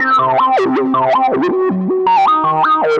Index of /musicradar/future-rave-samples/160bpm
FR_Cheeka_160-C.wav